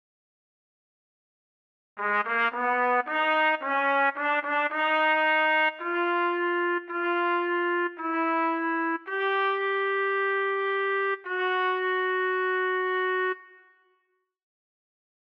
Key written in: B Major
Type: Barbershop
Each recording below is single part only.